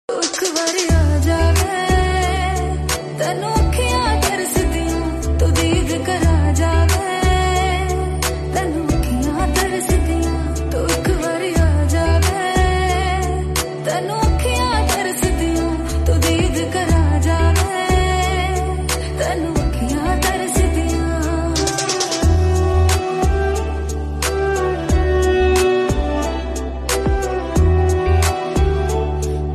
Punjabi Songs